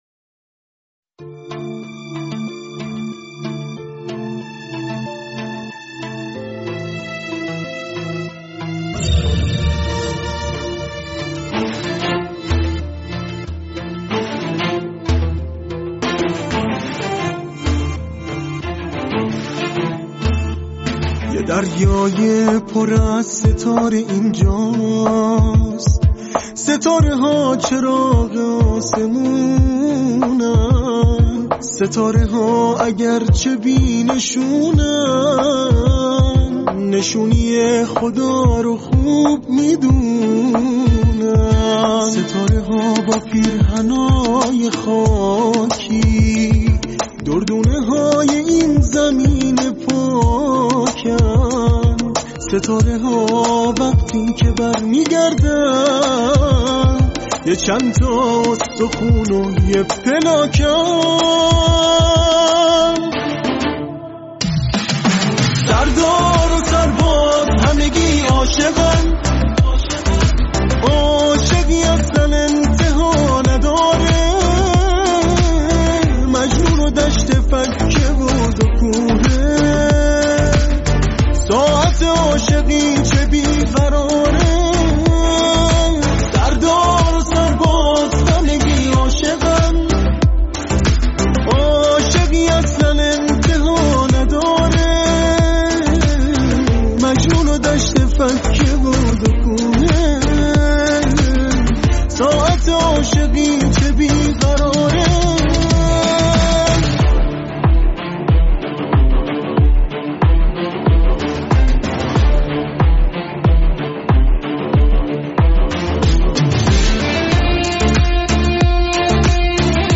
ترانه ی پاپ